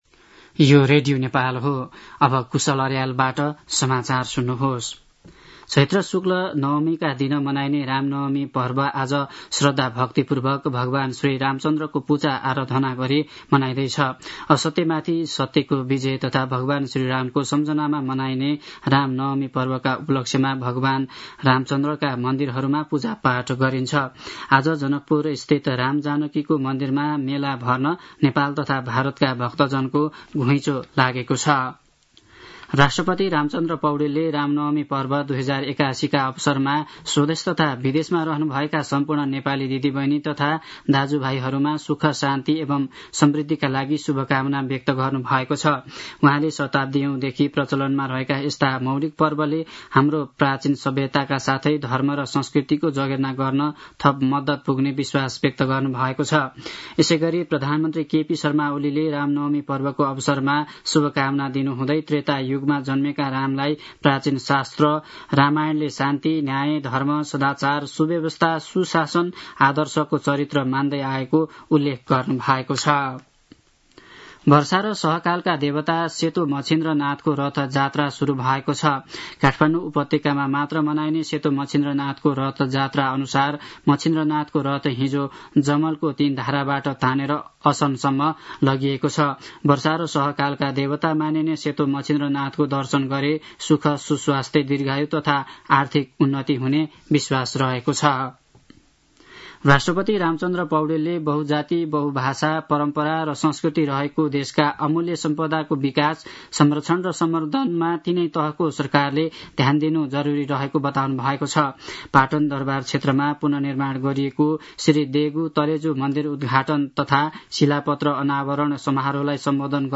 मध्यान्ह १२ बजेको नेपाली समाचार : २४ चैत , २०८१